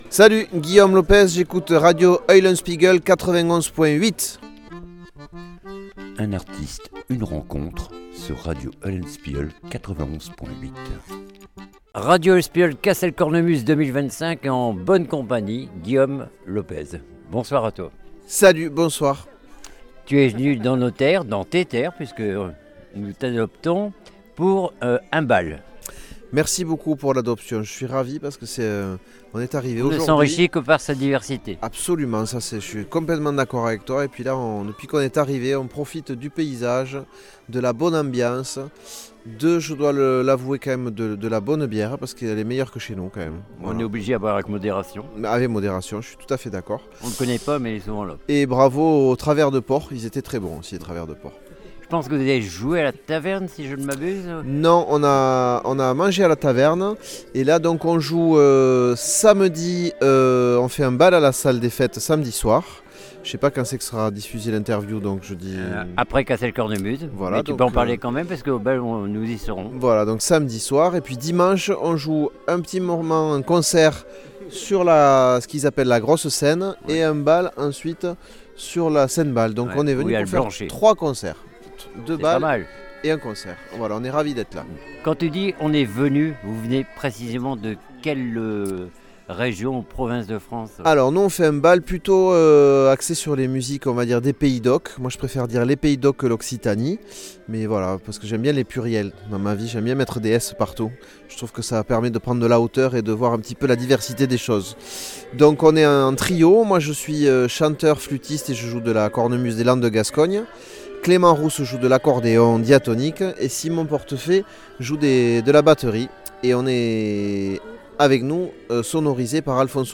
RENCONTRE LORS DU FESTIVAL CASSEL CORNEMUSES 2025